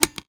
machinegun_empty.wav